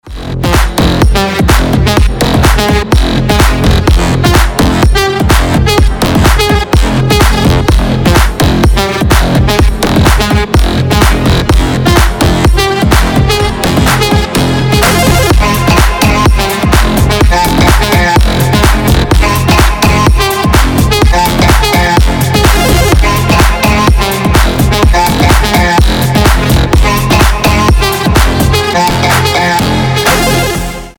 • Качество: 320, Stereo
мужской голос
Electronic
Стиль: future house